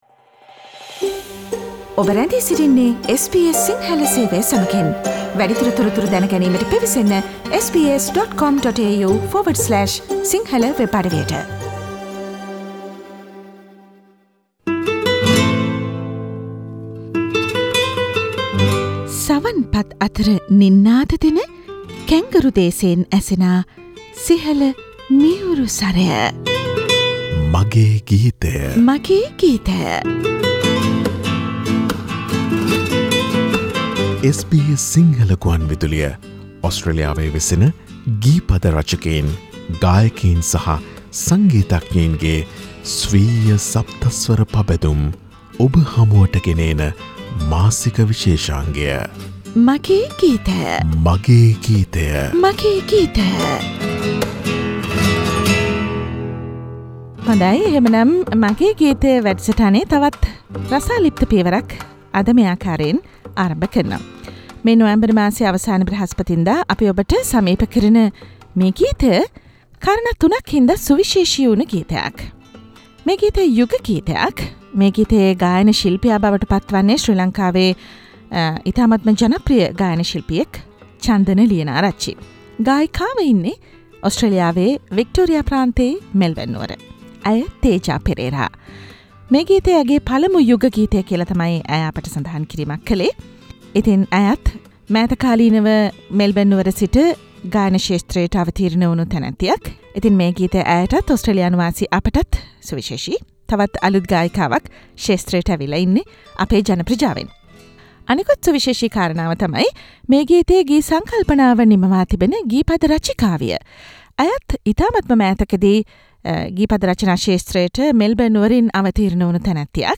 "My Song" - SBS Sinhala Radio monthly program.